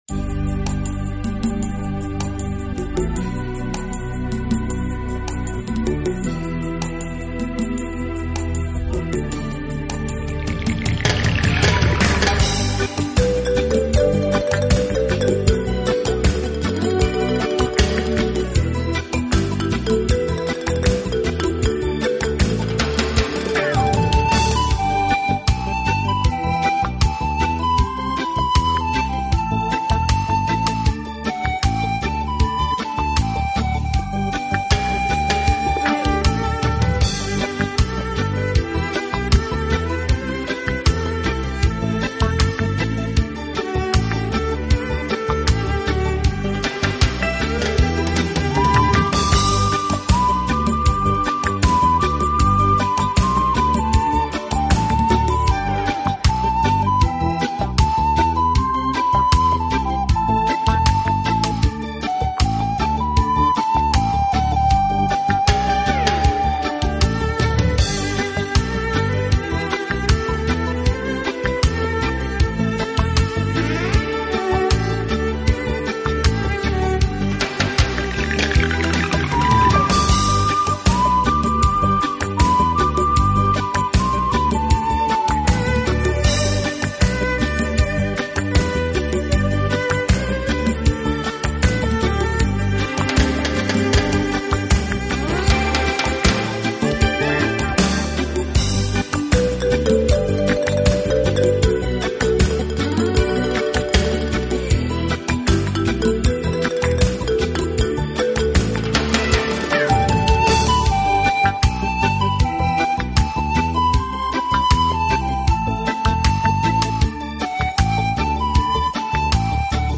Genre: Instrumental, Classical